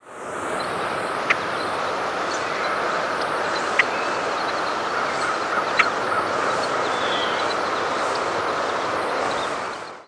Boat-tailed Grackle diurnal flight calls
Bird in flight with Yellow-rumped Warbler and American Crow calling in the background.